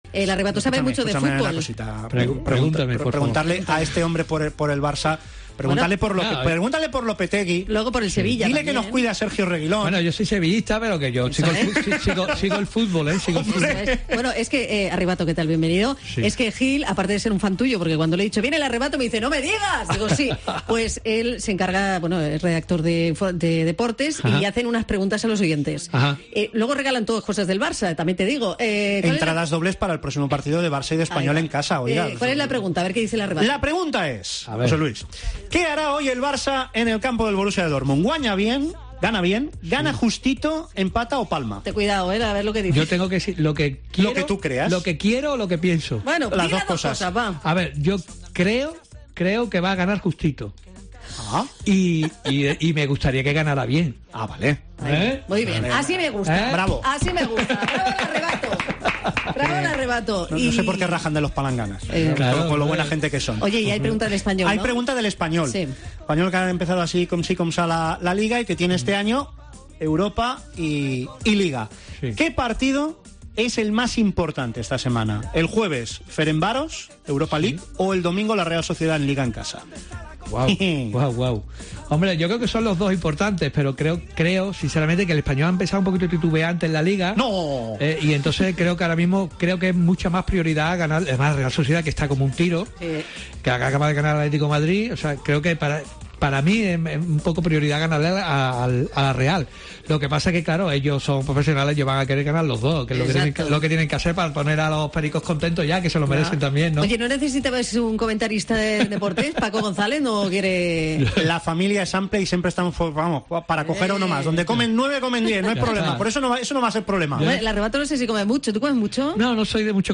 Vint anys de carrera i la mateixa energia que quan va començar. Això ens diu “El Arrebato”, que ha passat pels micròfons d'Herrera en Cope Catalunya i Andorra.